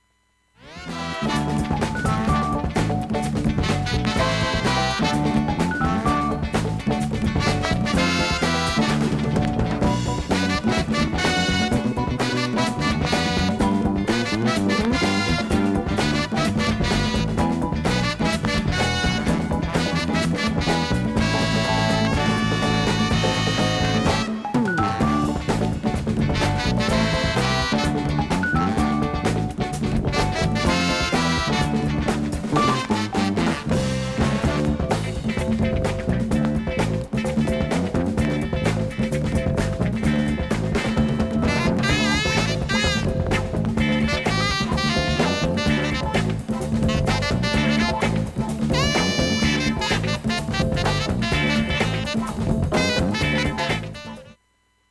A-3序盤に4ミリ弱の鼻毛の先のような
薄いスレで55秒の間にわずかなプツが
現物の試聴（上記録音時間1分）できます。音質目安にどうぞ